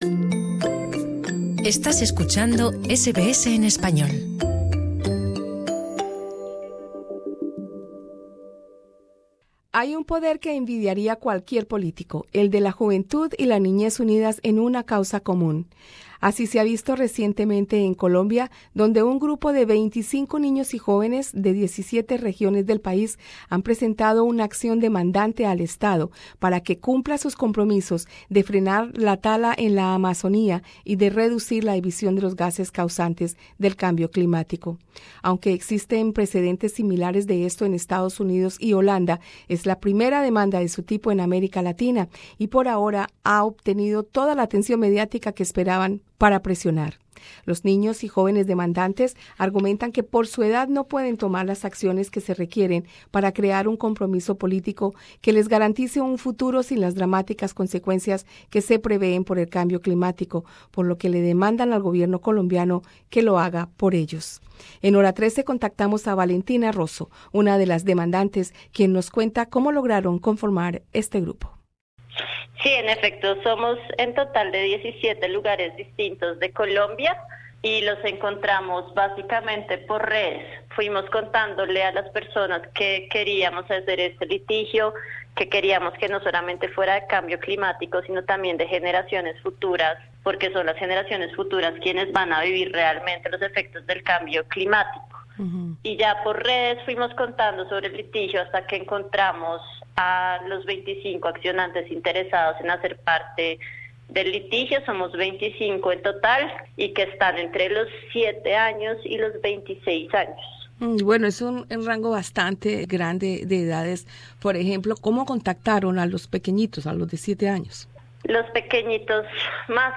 Escucha en nuestro podcast la entrevista